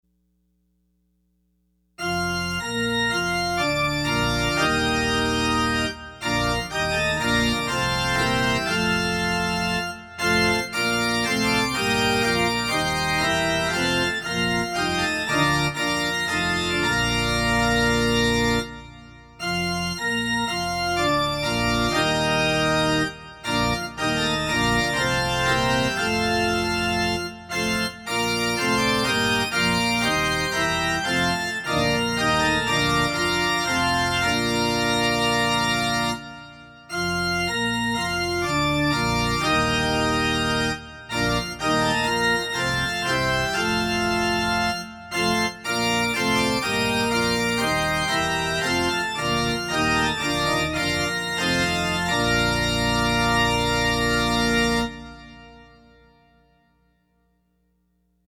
Closing Hymn – Rise up, ye saints of God!